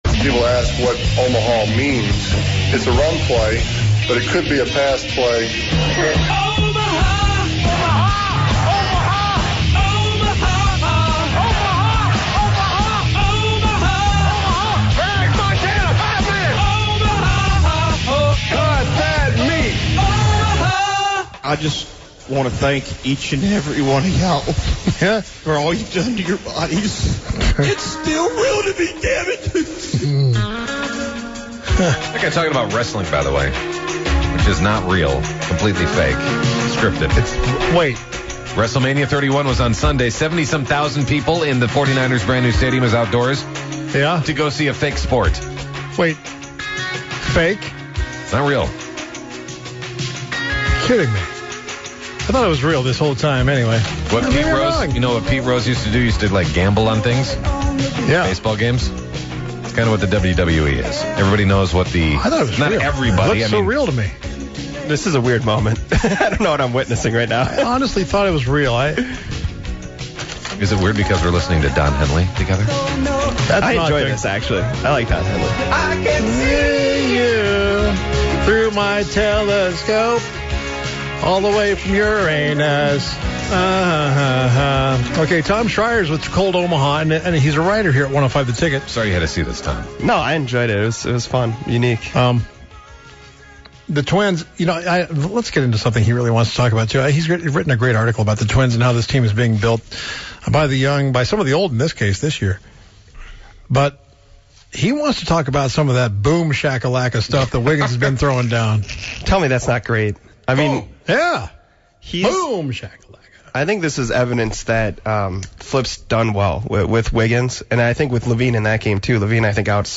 they play NFL Draft trivia with two callers for tickets. The show wraps up with the sillier side of sports and The Superstar's True Superstar of the Day.